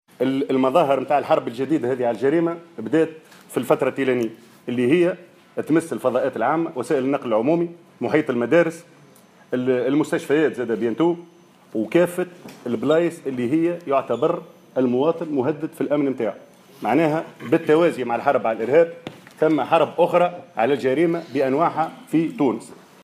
Lors d'une conférnence de presse tenue vendredi